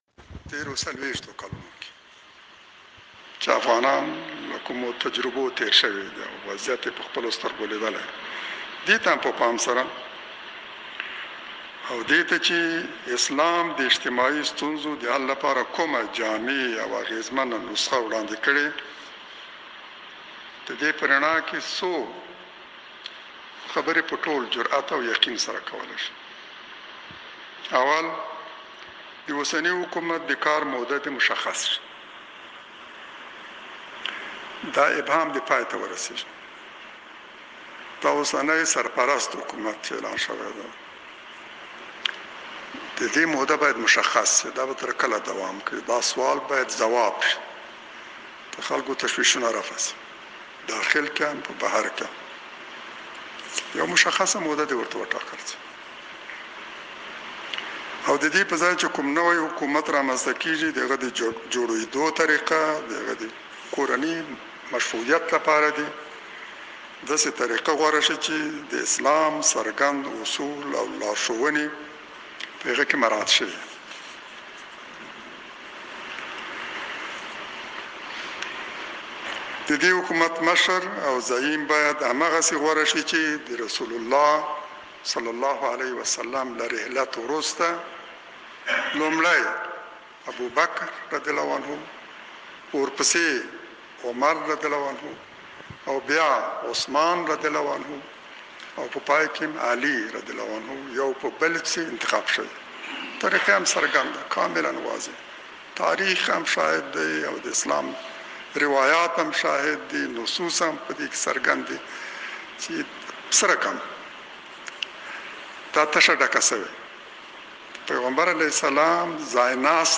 لاندې غږ د ښاغلي حکمتیار د څرګندونو دی: